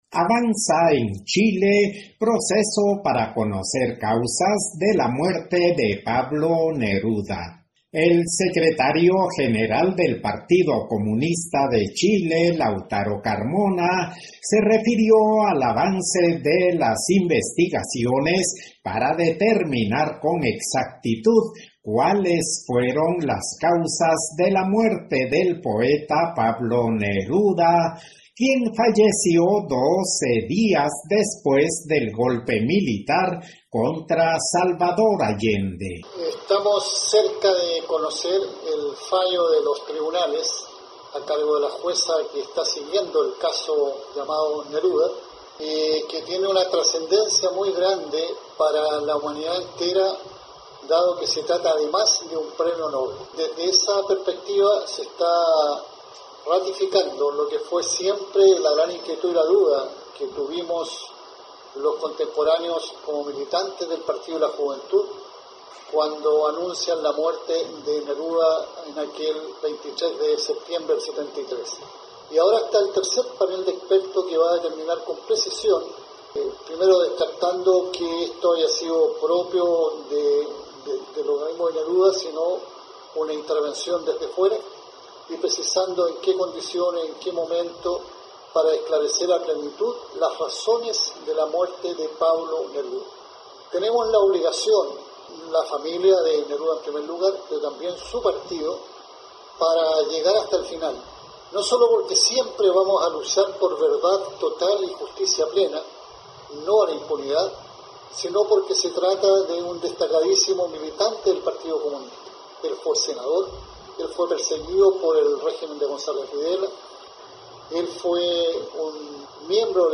El secretario general del Partido Comunista de Chile, Lautaro Carmona, se refirió al avance en las investigaciones para determinar con exactitud cuáles fueron las causas de la muerte del poeta Pablo Neruda, quien falleció 12 días después del golpe militar contra Salvador Allende.